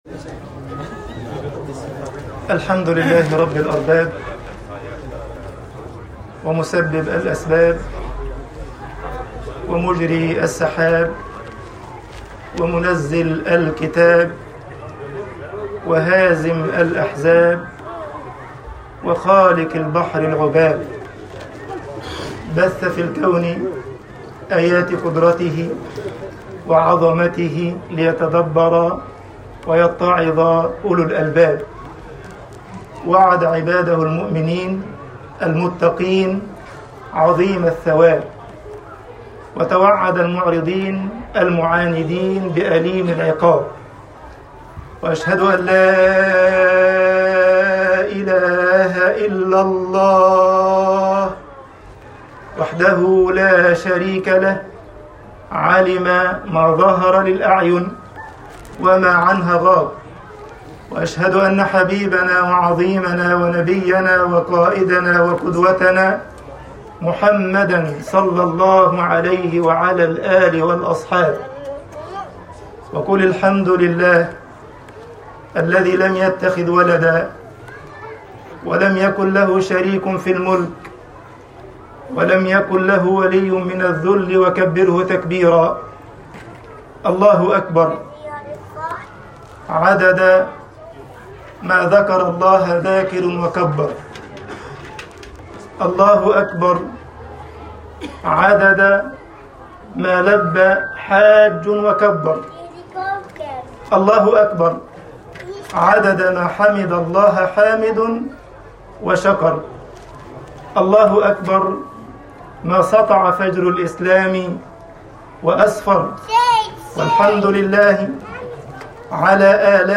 خطبة عيد الأضحى 1443هـ (يّا أبَتِ افْعَلْ مَا تؤمَر)